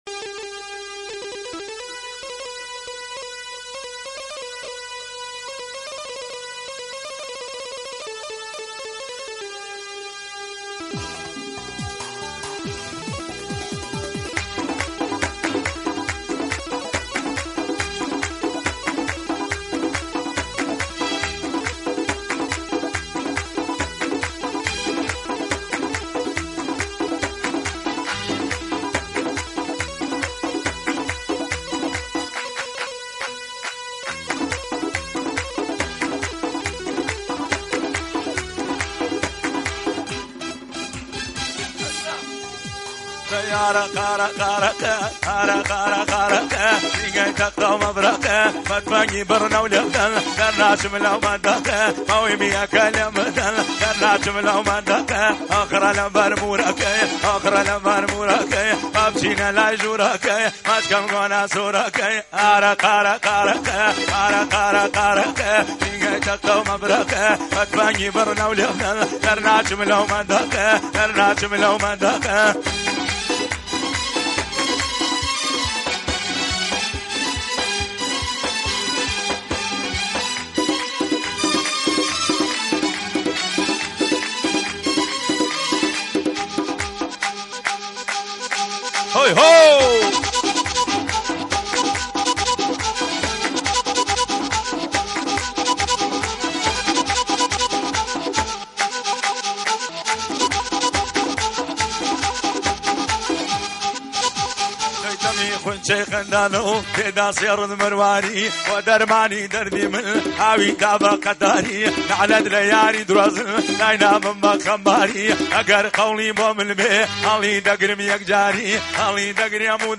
یک قطعه موسیقی شاد کردی